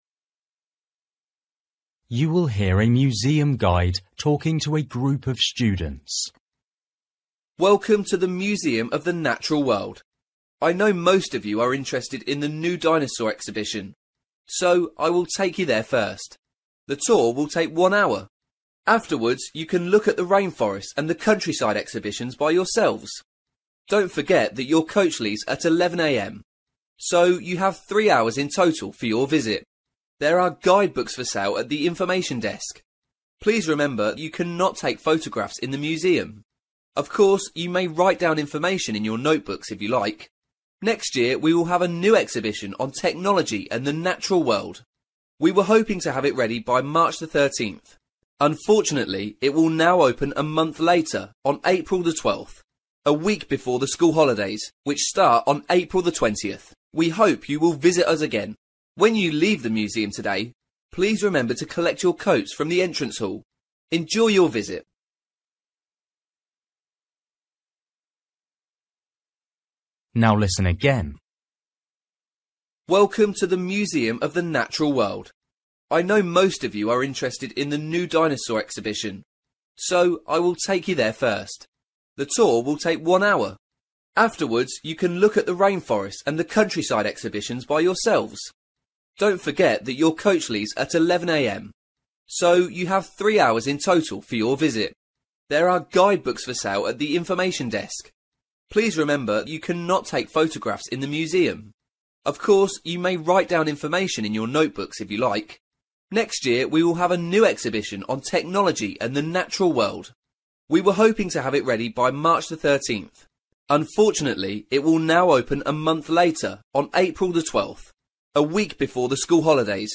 You will hear a museum guide talking to a group of students.